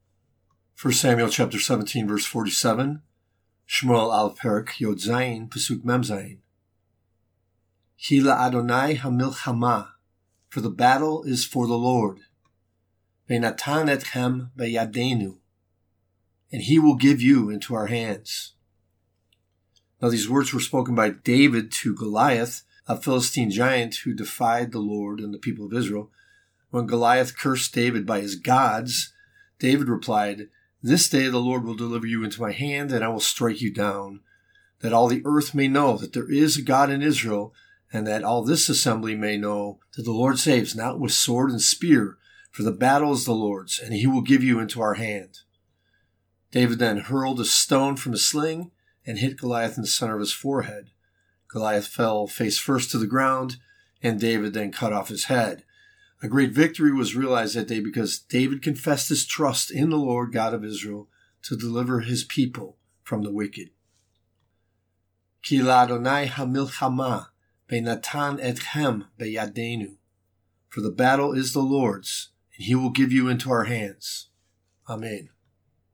1 Samuel 17:47b reading: